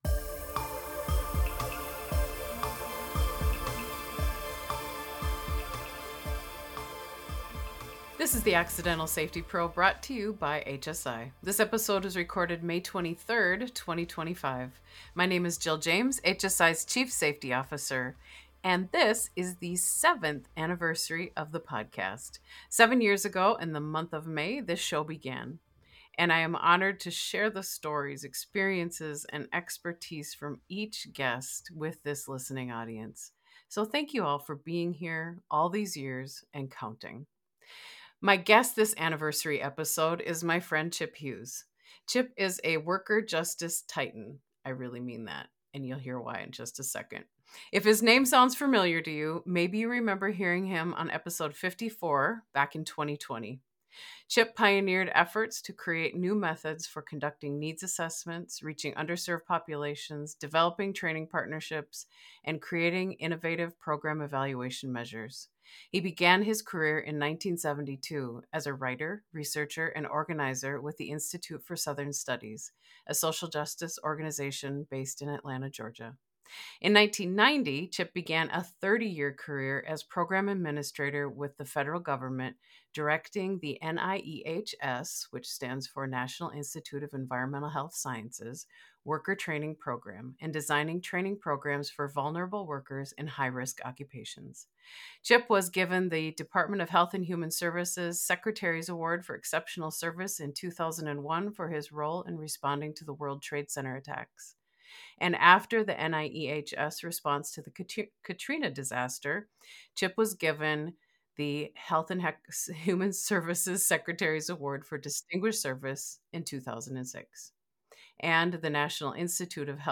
This safety podcast is a series of conversations with safety professionals about how they came into their role, what they've learned along the way, as well as some of the highs and lows that come with job.